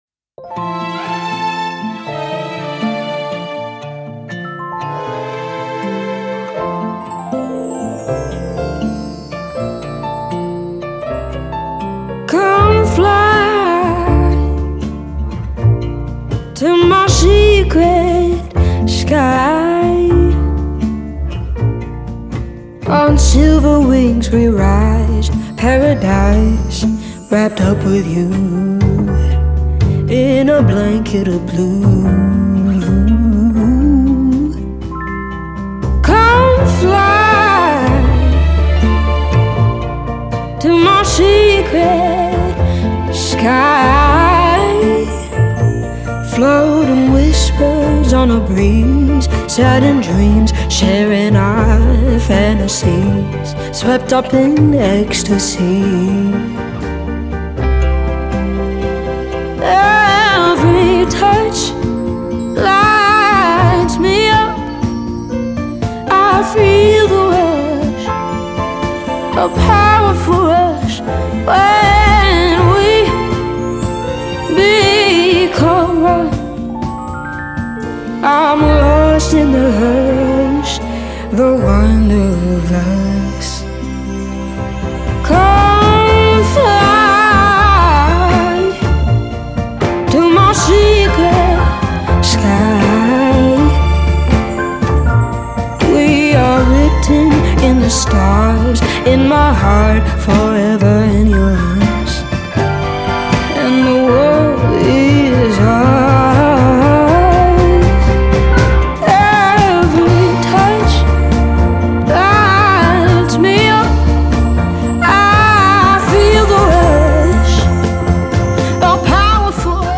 MASTER RECORDINGS - Country
Modern Country